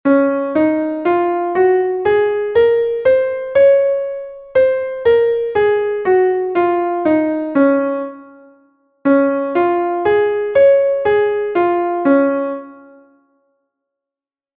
2º Despois buscamos a tonalidade, e cantamos a escala e o arpexio.
escala_arpegio_reb_maior.mp3